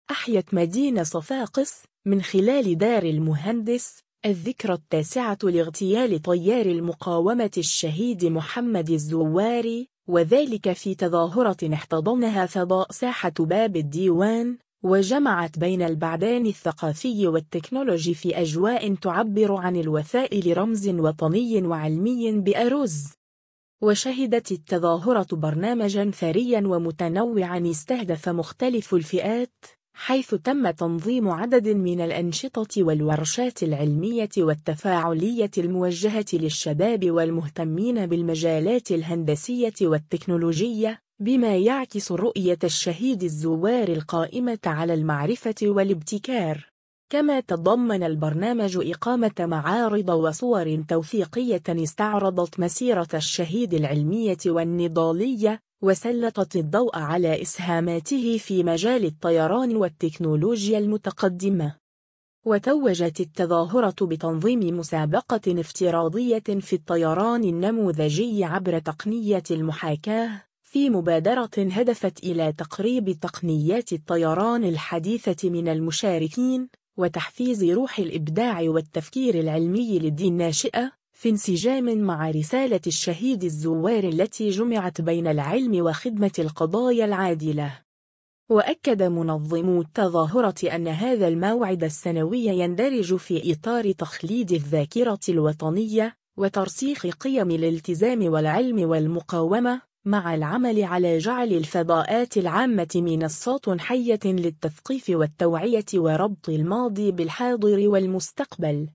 أحيت مدينة صفاقس، من خلال دار المهندس، الذكرى التاسعة لاغتيال طيّار المقاومة الشهيد محمد الزواري، وذلك في تظاهرة احتضنها فضاء ساحة باب الديوان، وجمعت بين البعدين الثقافي والتكنولوجي في أجواء تعبّر عن الوفاء لرمز وطني وعلمي بارز.